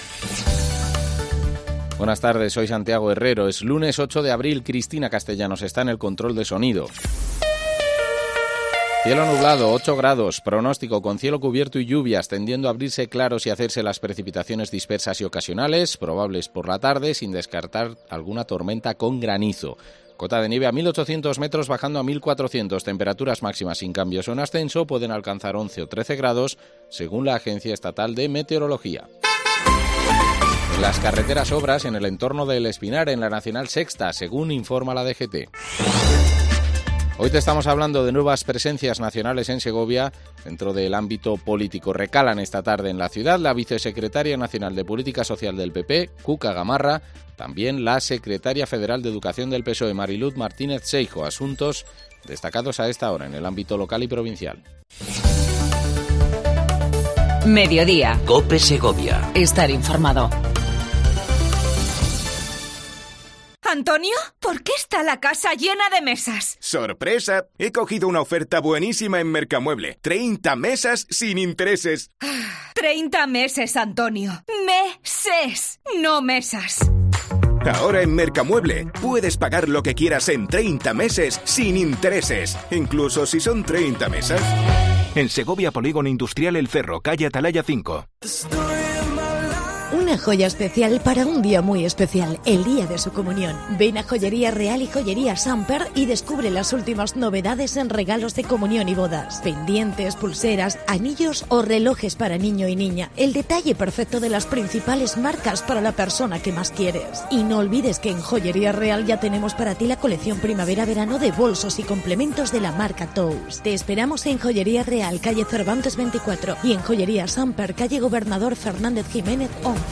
Entrevista a Clara Luquero, Alcaldesa de la capital segoviana con la que hablamos de la actualidad de la provincia.